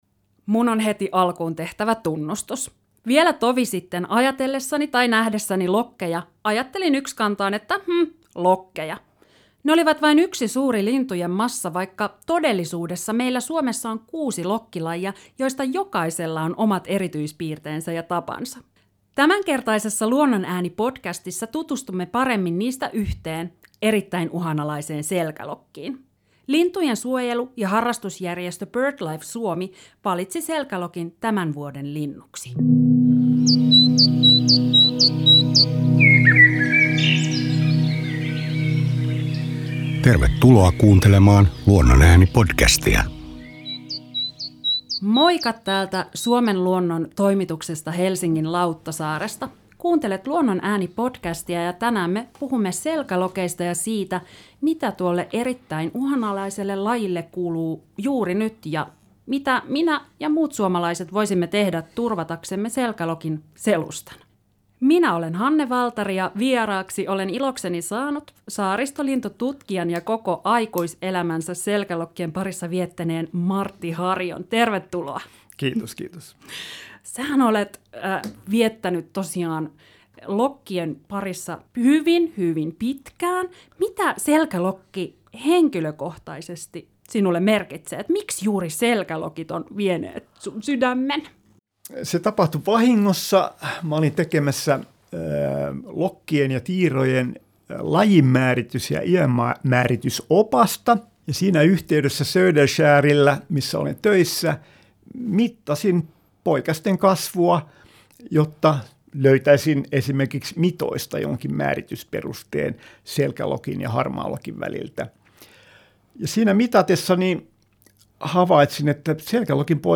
saa studioon vieraakseen lintututkijan